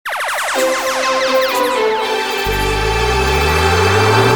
Интро музыкальной подложки к подкастам интернет-портала о страховании и финансах.